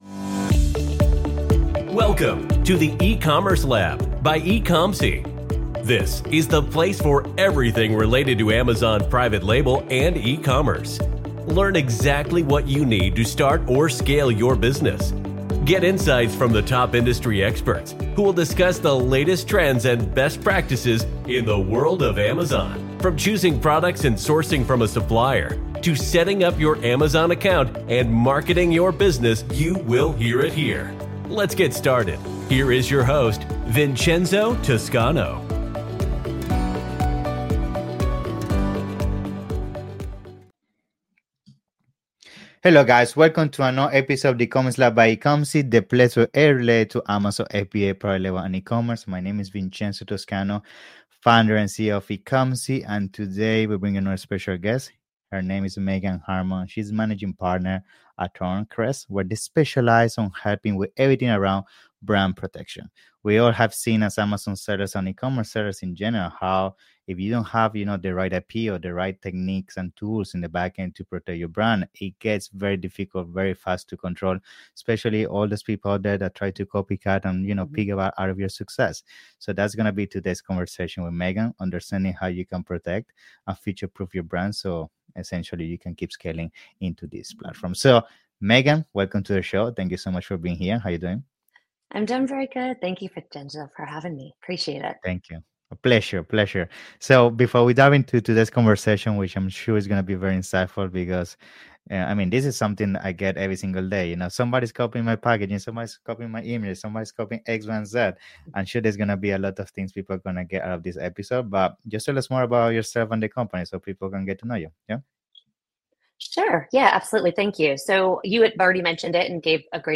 During this interview